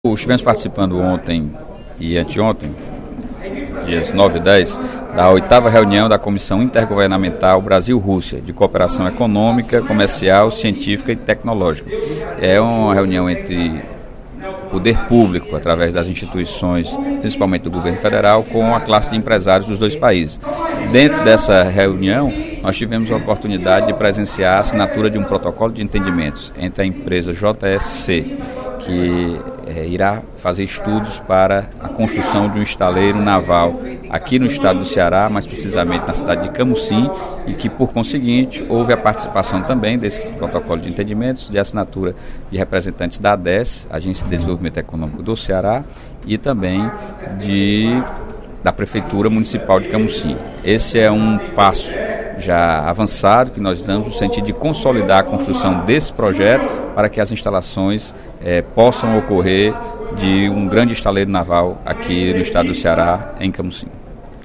O projeto de implantação de um estaleiro em Camocim foi discutido na VIII Reunião da Comissão Intergovernamental Brasil-Rússia de Cooperação Econômica, Comercial, Científica e Tecnológica (CIC), que ocorreu nesta terça-feira (10/12), em Brasília. A informação foi prestada pelo deputado Sérgio Aguiar (Pros), no primeiro expediente da sessão plenária da Assembleia Legislativa desta quarta-feira (11/12).
Em aparte, o deputado Fernando Hugo (SDD) elogiou o trabalho de Ciro Gomes à frente da Secretaria de Saúde e a rapidez em resolver a superlotação dos hospitais públicos de Fortaleza.